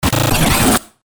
FX-1441-BREAKER
FX-1441-BREAKER.mp3